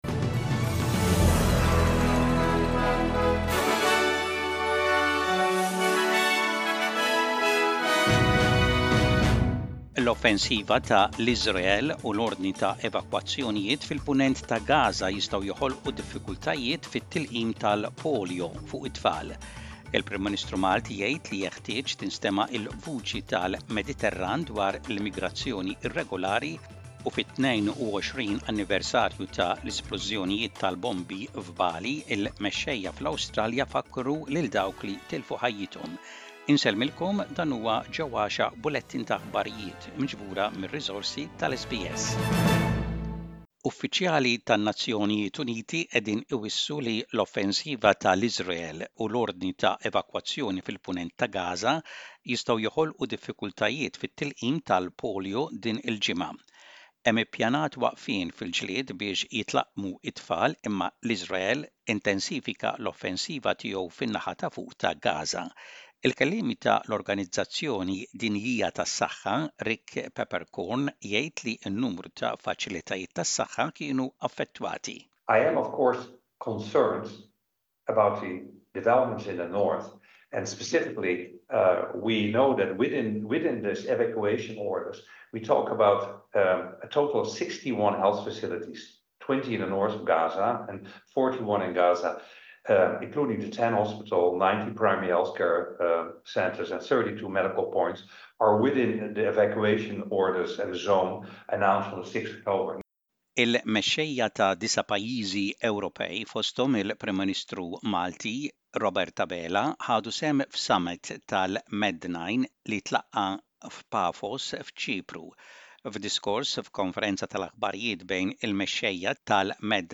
SBS Radio | Aħbarijiet bil-Malti: 15.10.24